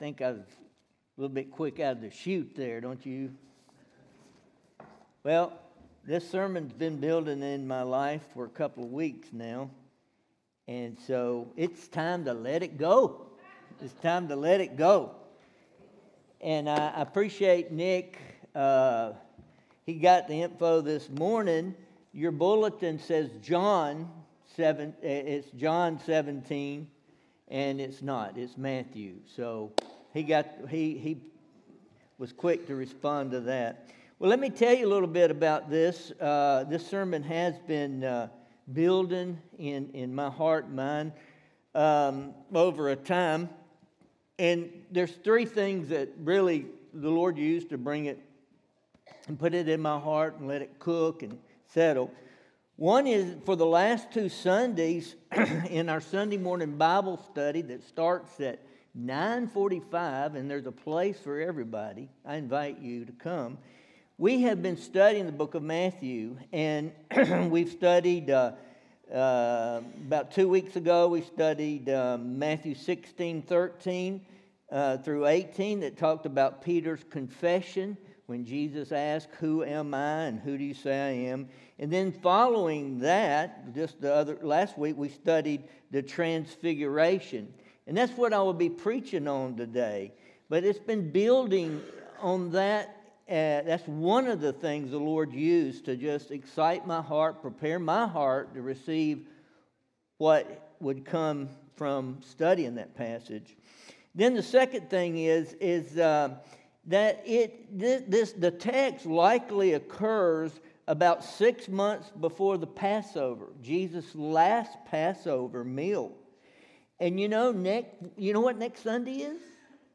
Sermon Audio Only